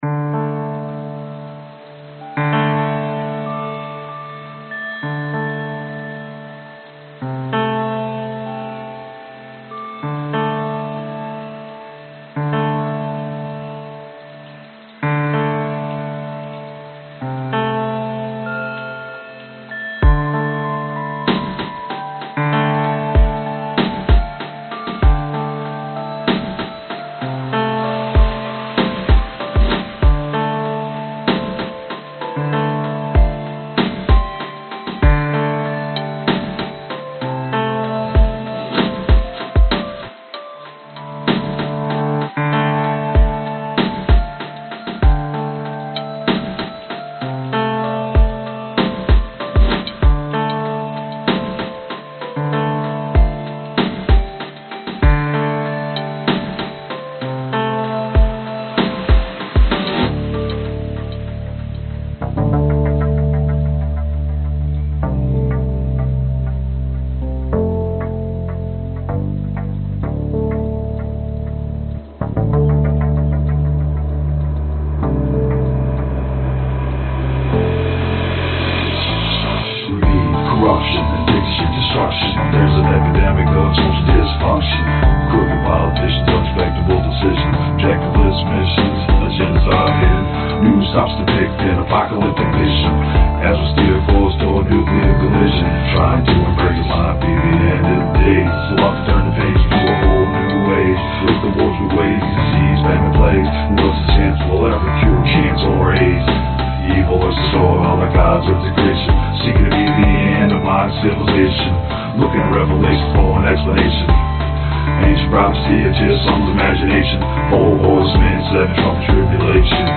Tag: 慢节奏 男声 口语 合成器